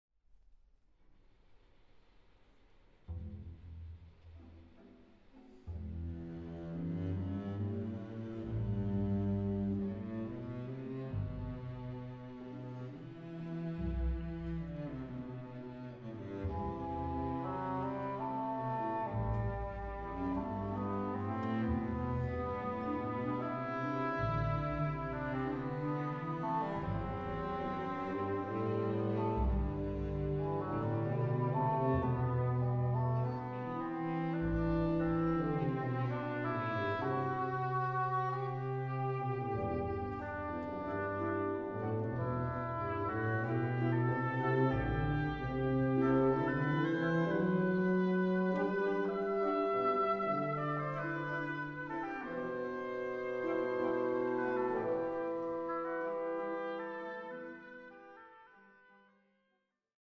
Recording: Katharina-Saal, Stadthalle Zerbst, 2025
für großes Orchester